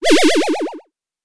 CartoonGamesSoundEffects